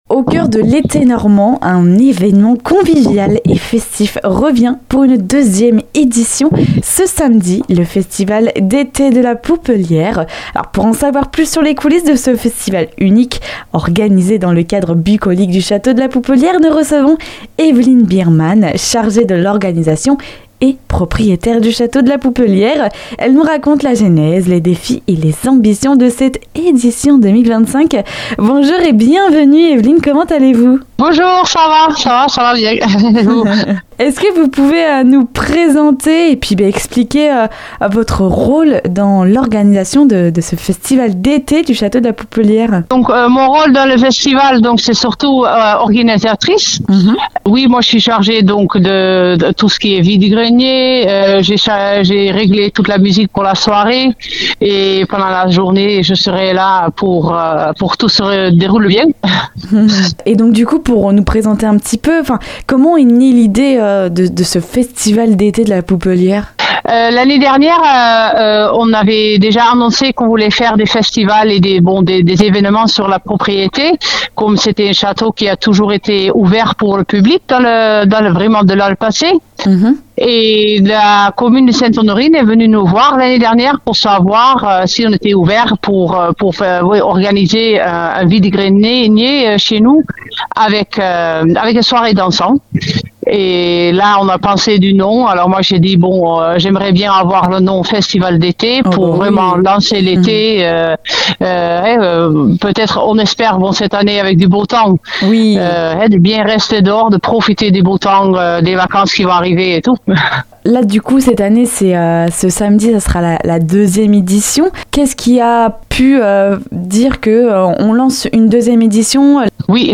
Un festival riche d'activités pour ce samedi 28 juin ! Pour en savoir plus sur cet rencontre n'hésitez pas à écouter jusqu'au bout l'interview et à partir flâner au Château de la Poupelière.